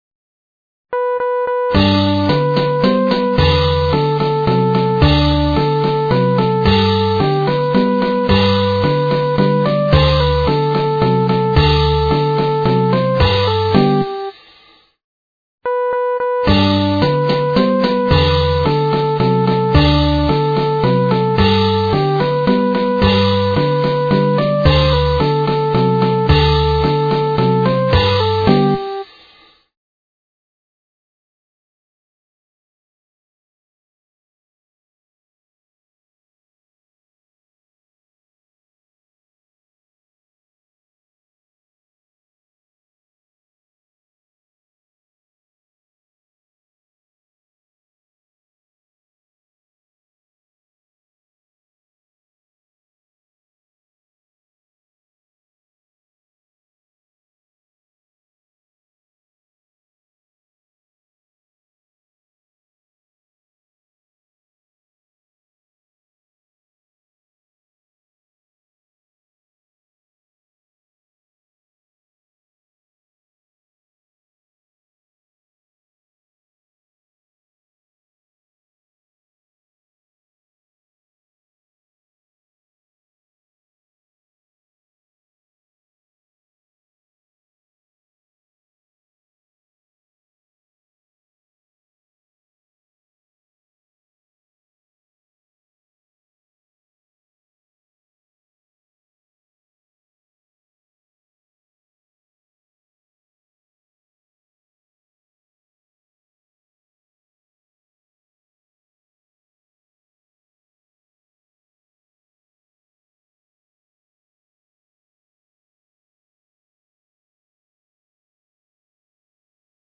Note: Each line is repeated, thus the first verse would be,